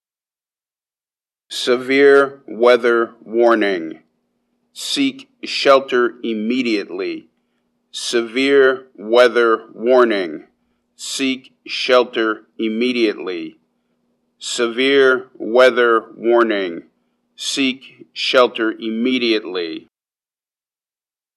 The alert tones are very loud and distinct and should be heard by anyone who is outdoors on the main Tallahassee campus. The alert tone may/may not be followed by voice instructions.
Examples of the warning alert sounds and voice instructions which may be played: